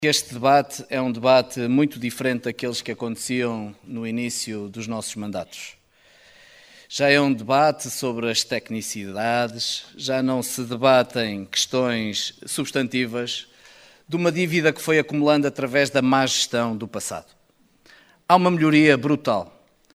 O relatório de contas relativo ao ano de 2021 foi aprovado por maioria na última Assembleia Municipal de Caminha.